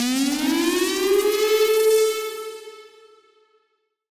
Index of /musicradar/future-rave-samples/Siren-Horn Type Hits/Ramp Up
FR_SirHornF[up]-A.wav